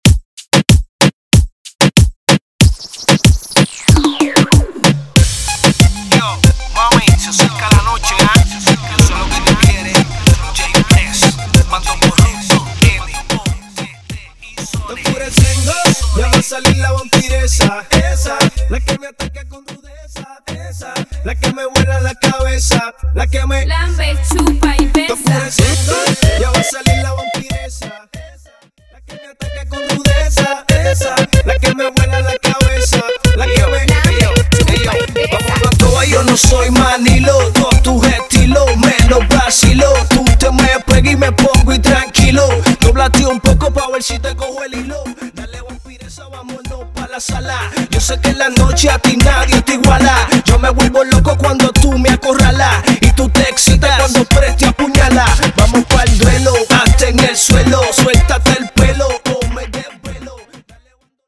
dynamic remixes